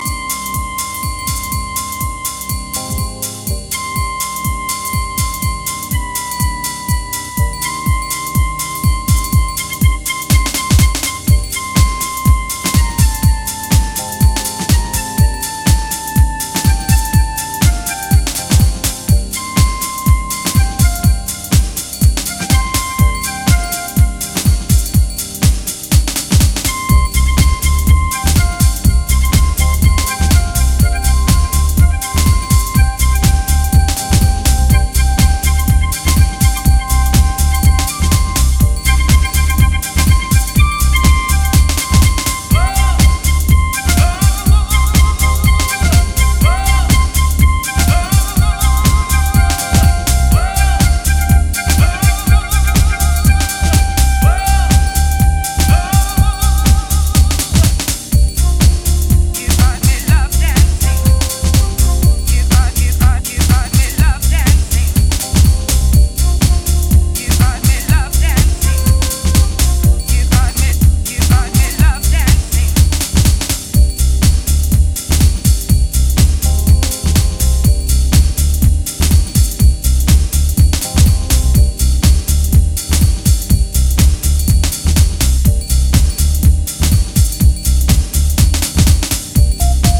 ジャンル(スタイル) HOUSE CLASSIC / DEEP HOUSE